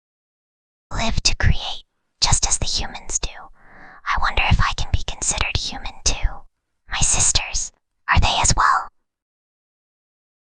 Whispering_Girl_33.mp3